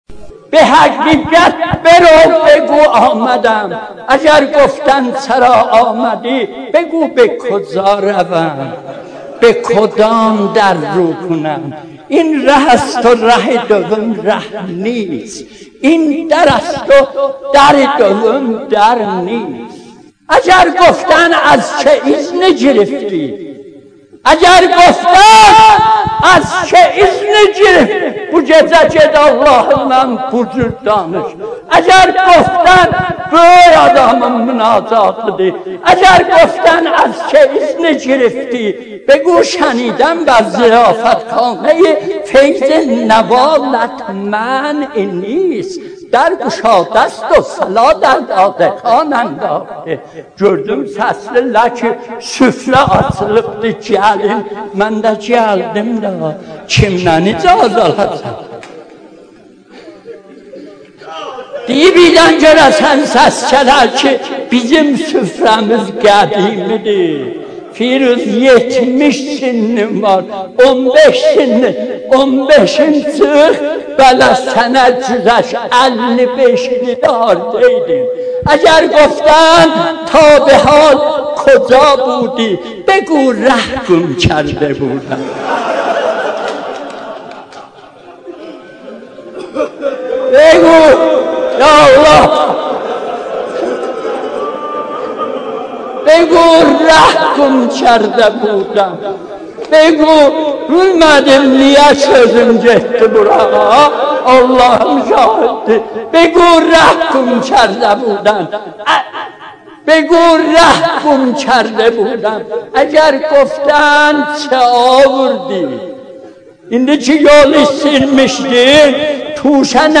مناجات7.mp3